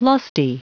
Prononciation du mot lusty en anglais (fichier audio)
Prononciation du mot : lusty